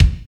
Index of /90_sSampleCDs/Northstar - Drumscapes Roland/DRM_R&B Groove/KIK_R&B Kicks x
KIK R B K01R.wav